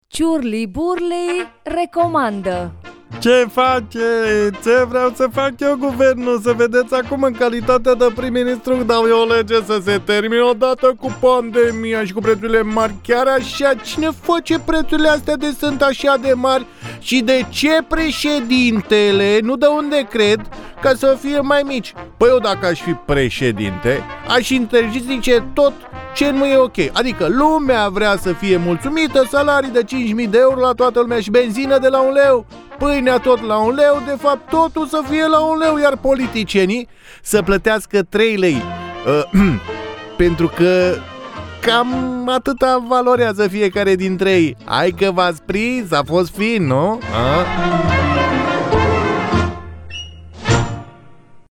Tot discursul, aici: